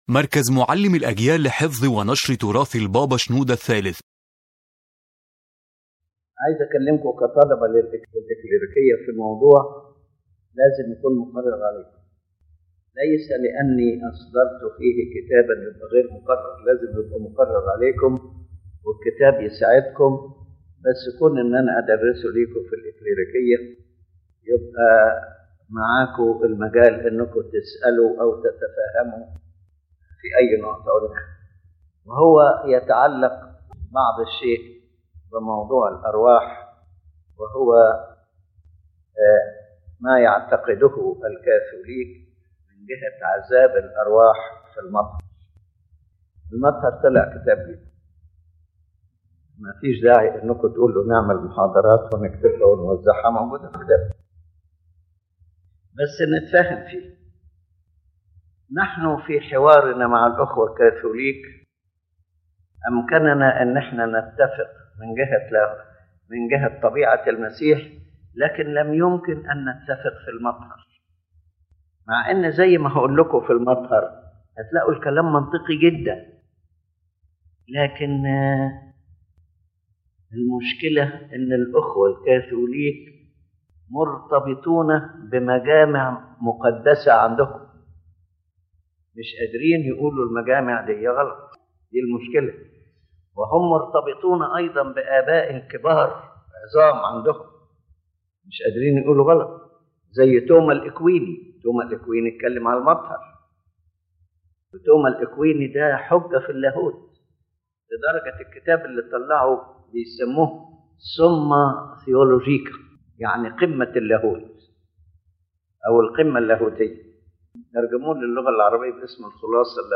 This lecture explains the position of the Coptic Orthodox faith regarding the doctrine of purgatory as taught in the Catholic Church, and presents the reasons for rejecting this doctrine from a theological and biblical perspective. His Holiness Pope Shenouda III explains that salvation in the Christian faith is completely based on the atonement and redemption of Christ, and that the blood of Christ alone purifies a person from sins, not suffering after death.